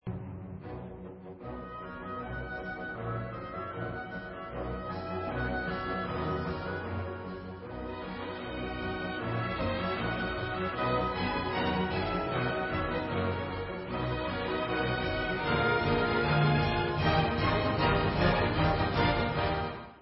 e moll (Allegretto grazioso) /Mazur